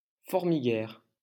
Formiguères (French pronunciation: [fɔʁmiɡɛʁ]